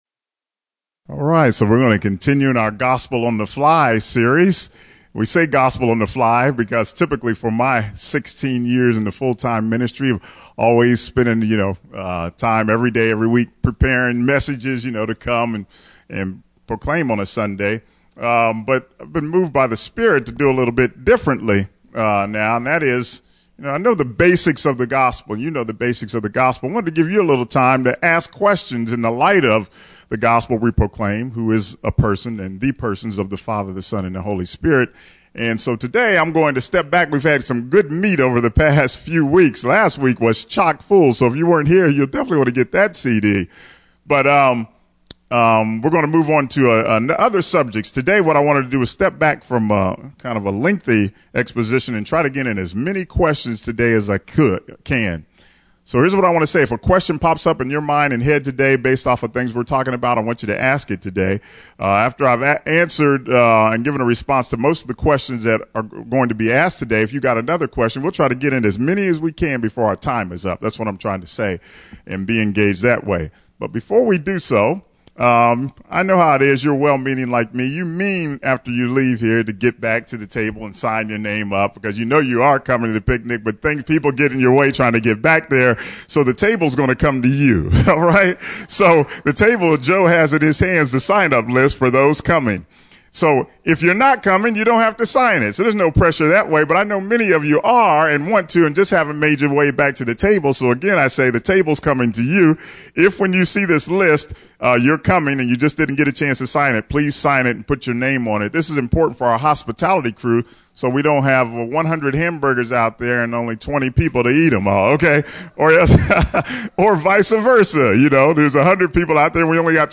Listen in as New Life Fellowship of Baltimore Maryland and some of its guests talk it out!